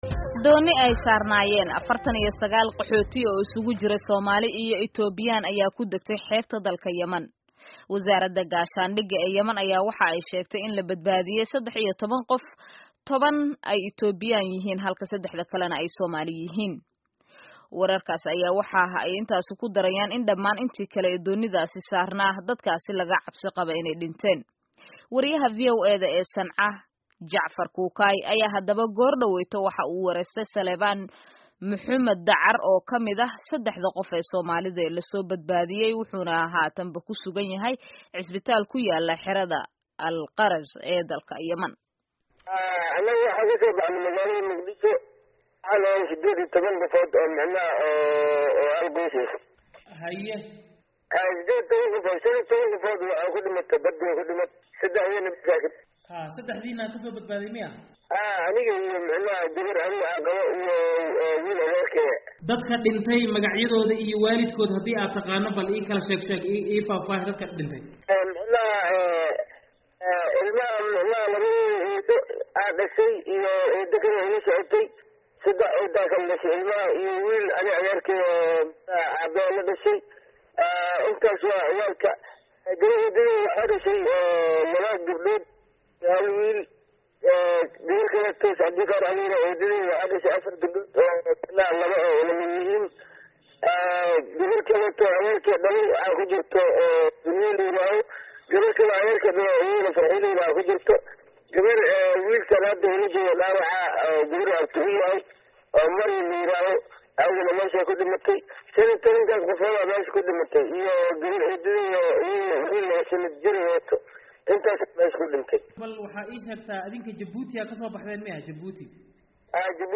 Dhegayso: Waraysi ku saabsan doonta ku degtay Yemen